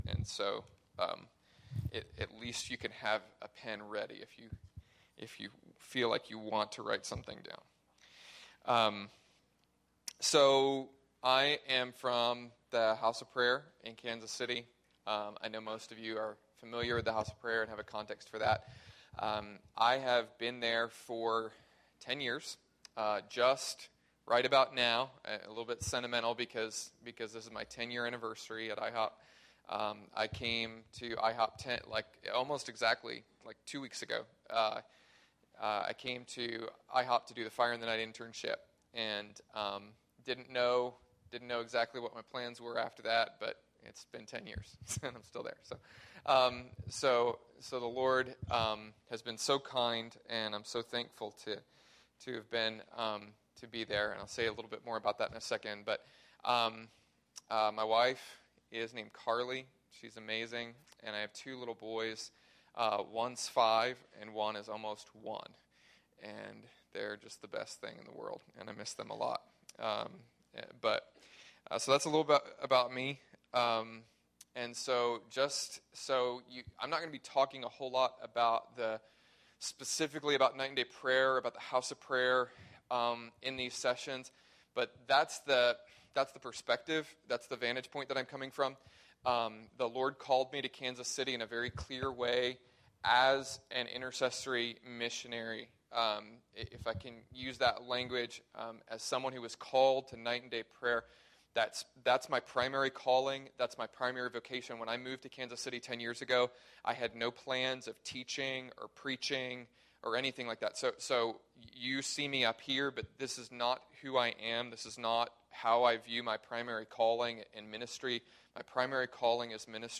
Spoken on September 14th, 2012 at the International House of Prayer East Bay . This is the first session of the Passion for Jesus series from the event held on this weekend.